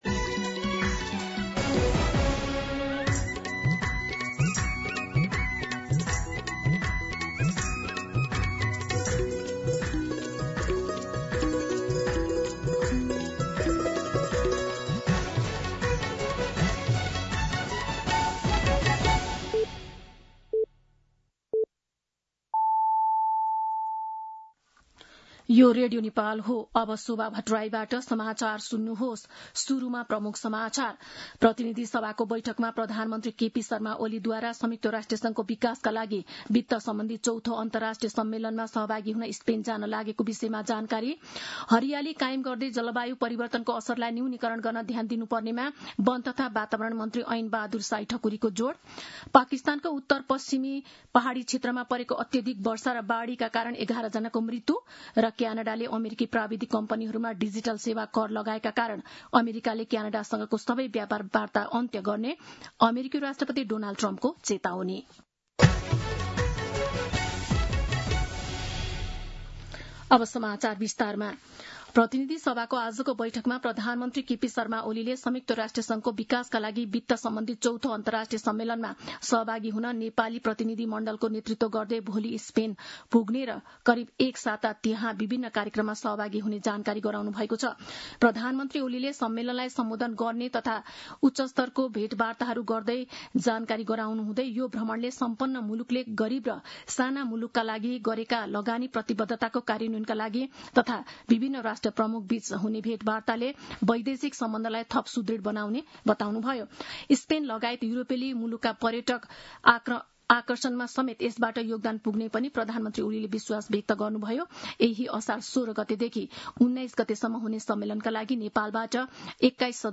दिउँसो ३ बजेको नेपाली समाचार : १४ असार , २०८२
3-pm-Nepali-News-3.mp3